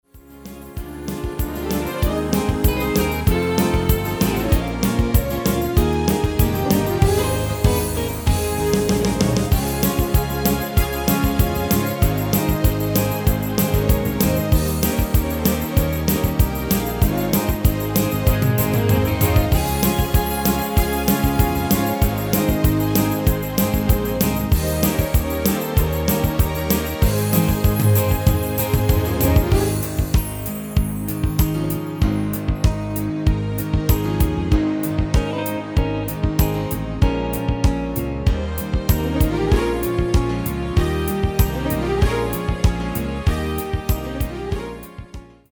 Demo/Koop midifile
Genre: Actuele hitlijsten
Toonsoort: Ab
Demo's zijn eigen opnames van onze digitale arrangementen.